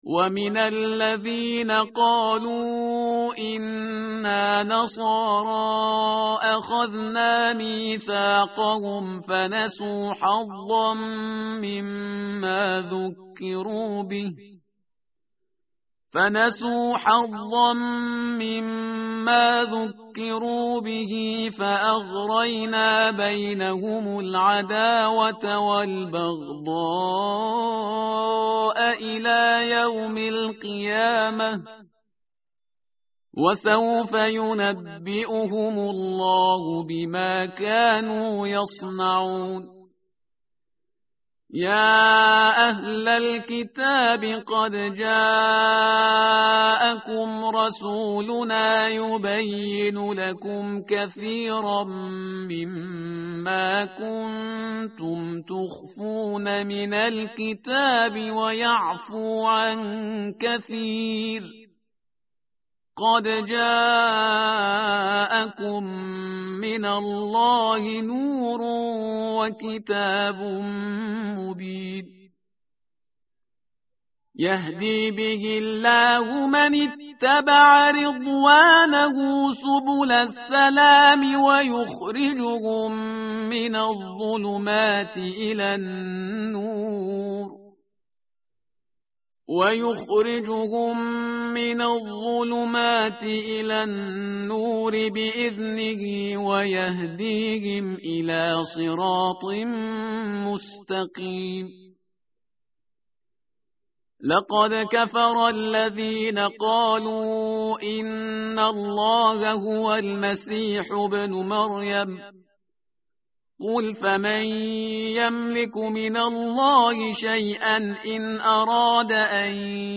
متن قرآن همراه باتلاوت قرآن و ترجمه
tartil_parhizgar_page_110.mp3